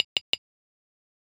フリー効果音：タップ
コップとか食器の音！陶器をカッカッと叩いたり、固い壁などを叩くシーンにぴったり！
tapping.mp3